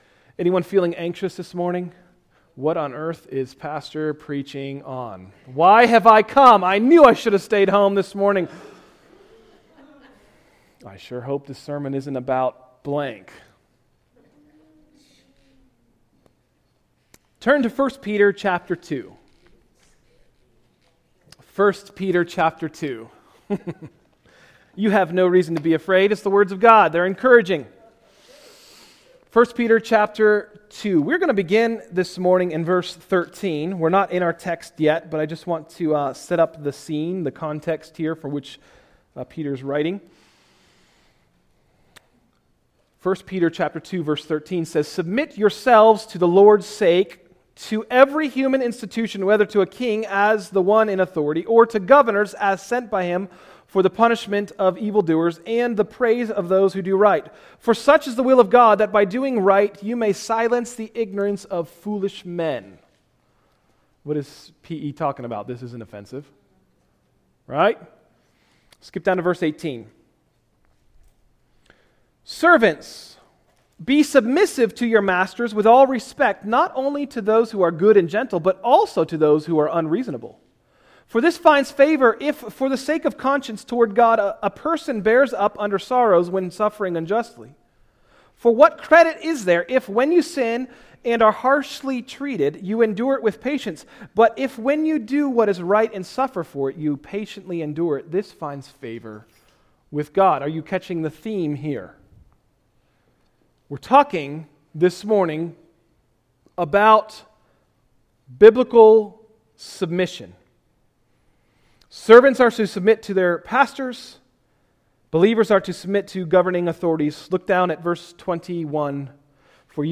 Message: “A Winning Marriage” – Tried Stone Christian Center